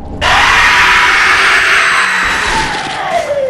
Dark Souls 3 Scream Sound Buttons
333-scare-alert.mp3